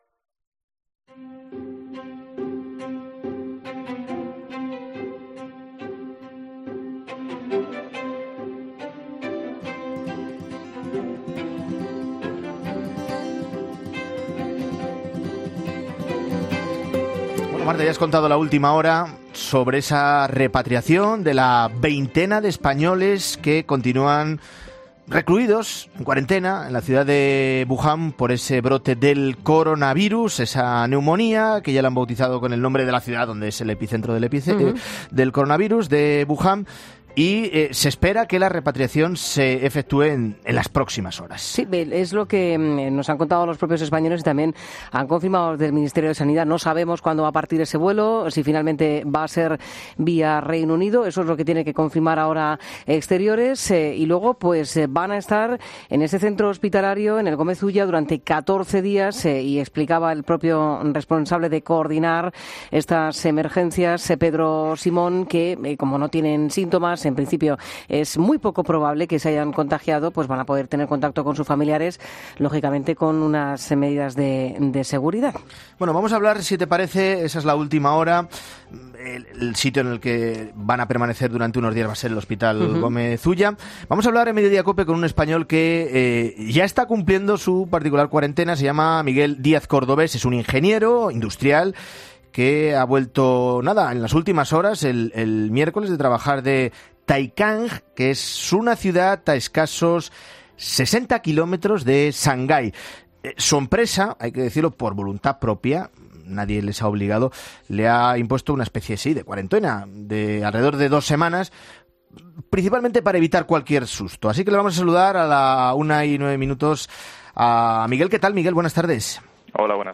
Un madrileño en cuarentena tras volver de China nos cuenta su experiencia en Mediodía COPE